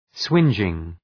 Προφορά
{‘swındʒıŋ} (Επίθετο) ● κολοσσιαίος